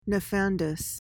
PRONUNCIATION:
(nuh-FAN-duhs)